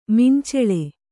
♪ minceḷe